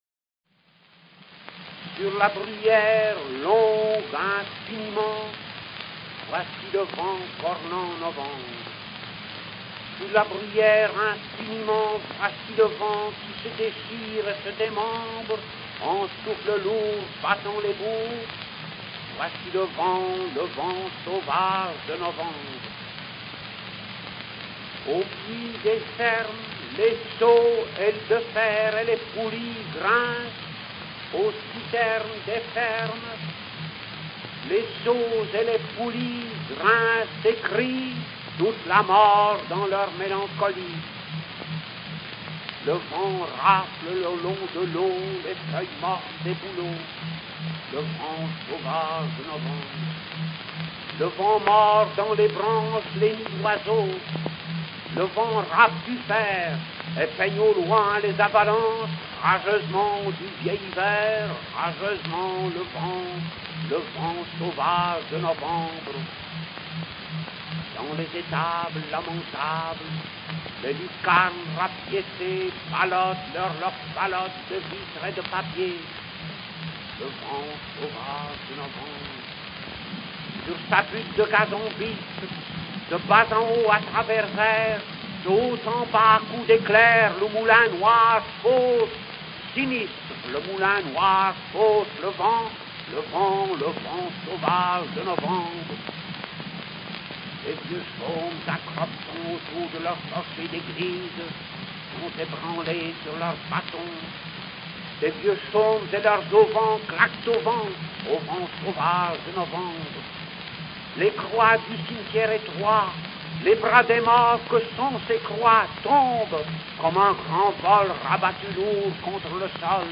Guillaume Apollinaire (1880-1918) Marie Le voyageur Emile Verhaeren (1855-1916) Le vent Le passeur d'eau Le registrazioni sono state tutte effettuate per gli Archivi della Parola dell'Università di Parigi nel 1913.